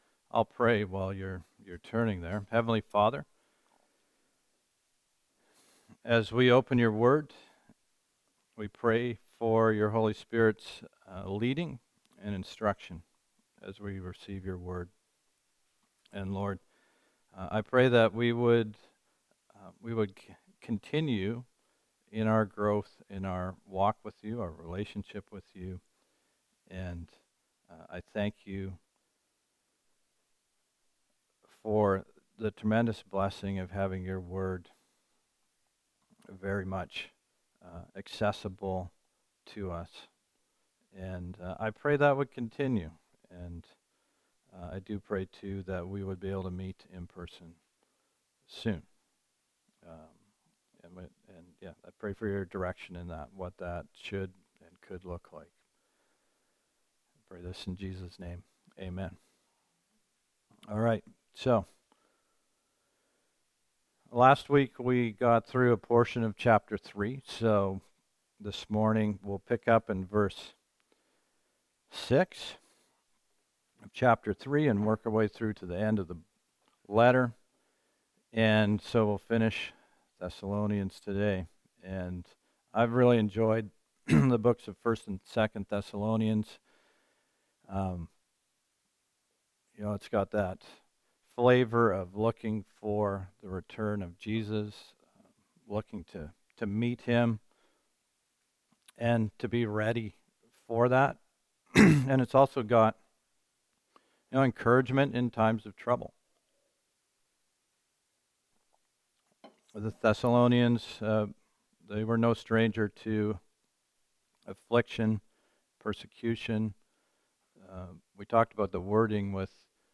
Sermons | Calvary Chapel Comox Valley